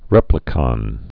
(rĕplĭ-kŏn)